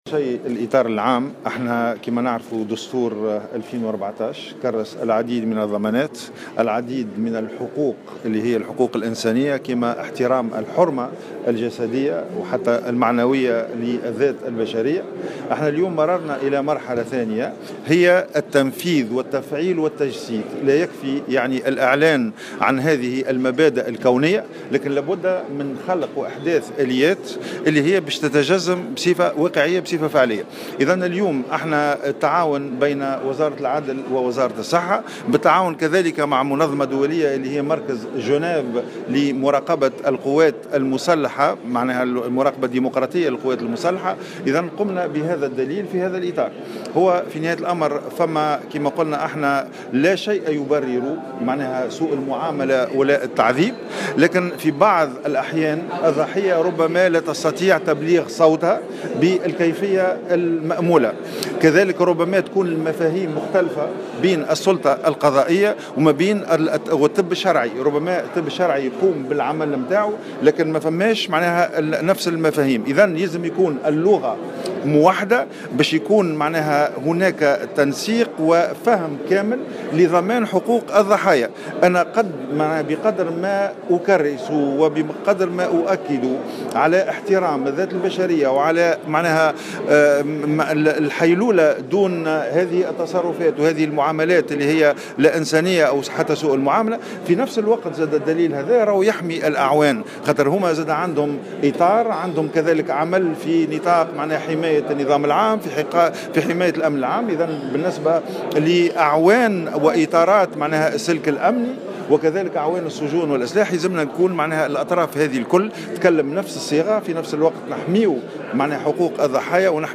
وأكد وزير العدل غازي الجريبي في تصريح لمراسل الجوهرة "اف ام" أنه حرص منذ توليه الوزارة على بقاء أي حالة تعذيب دون تتبع قضائي .وأضاف أن الأشخاص المسلوبة حريتهم ومهما كان سبب ايداعهم السجن يبقى لديهم حقوق ويجب أن يتم المحافظة عليها لأن حقوقهم مضمونة دستوريا والوزارة حريصة وساهرة على ضمان تلك الحقوق حسب قوله.